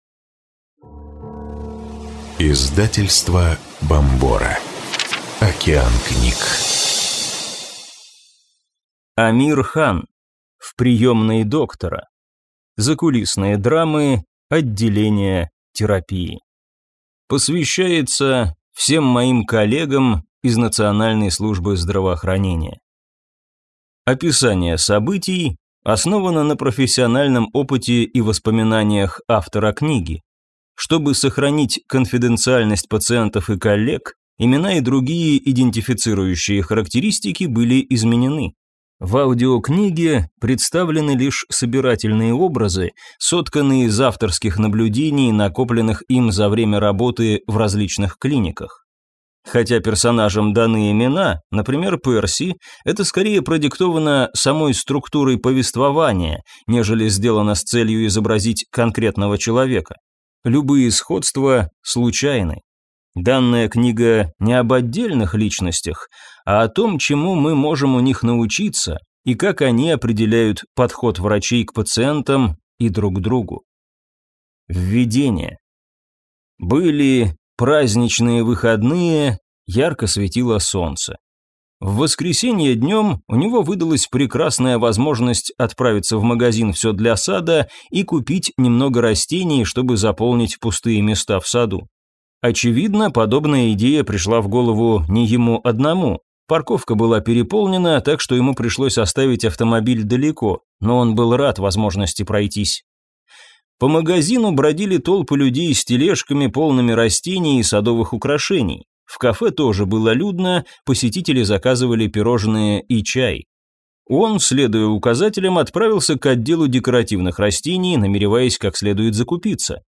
Аудиокнига В приемной доктора. Закулисные драмы отделения терапии | Библиотека аудиокниг